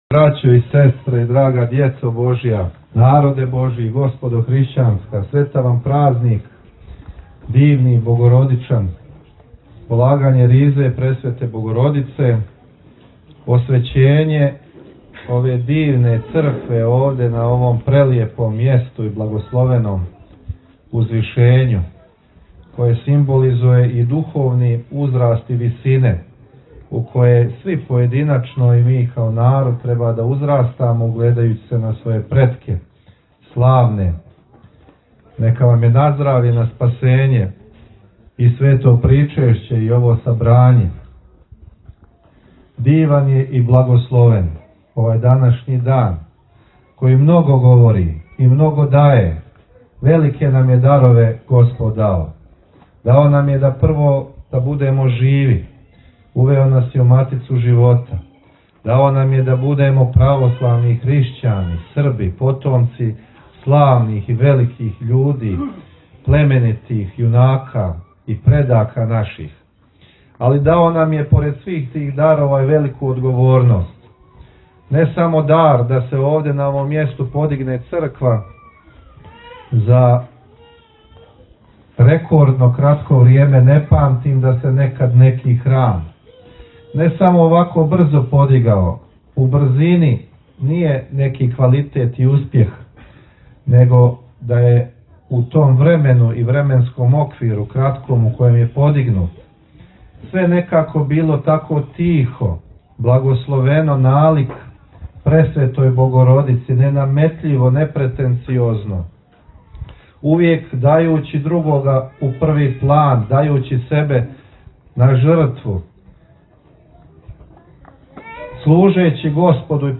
Njegova svetost Patrijarh srpski g. Porfirije načalstvovao je Svetom liturgijom i činom osvećenja obnovljenog Sabornog hrama Svete Trojice u Pakracu, […]